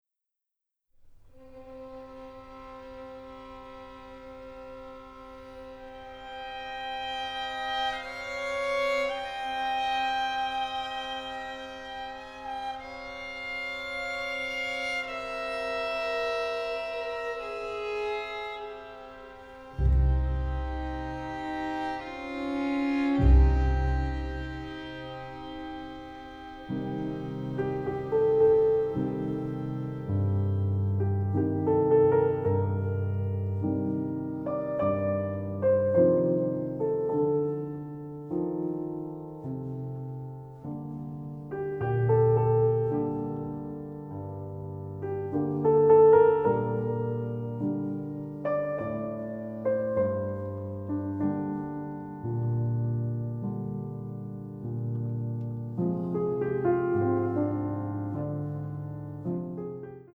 The score has been recorded in London